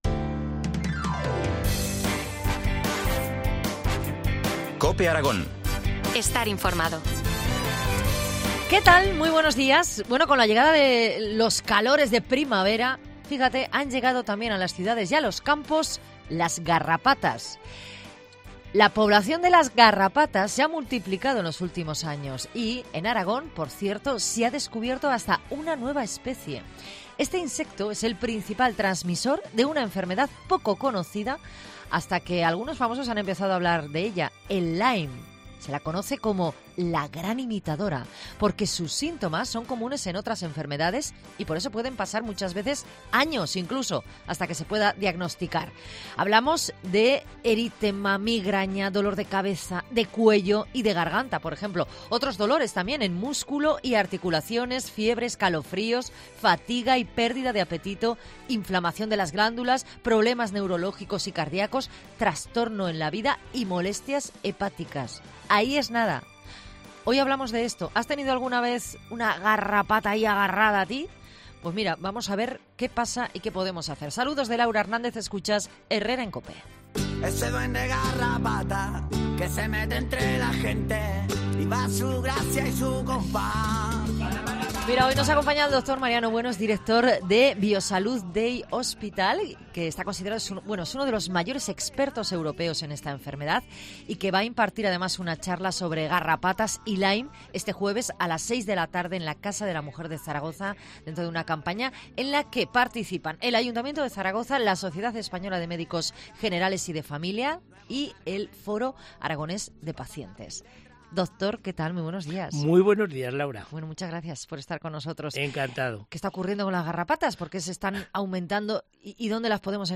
AUDIO: Entrevista del día en COPE Aragón